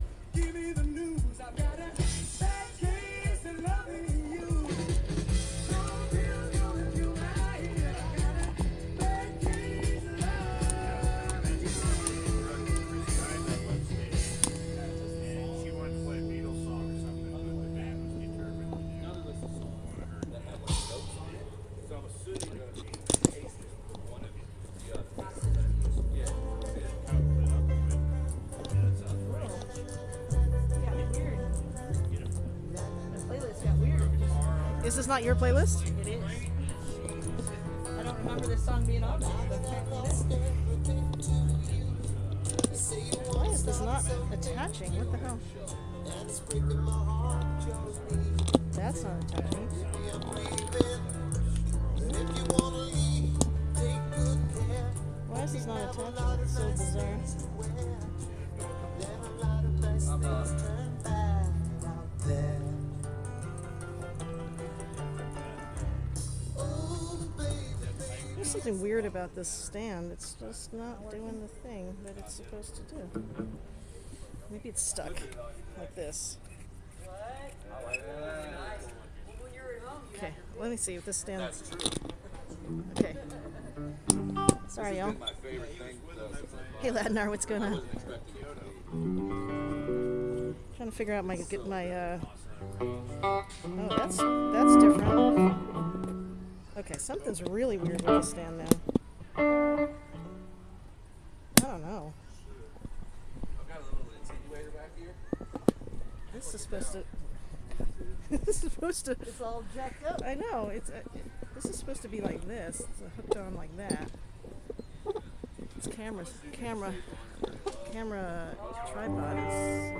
lifeblood: bootlegs: 2022-06-15: woodland park zoo - seattle, washington (soundcheck)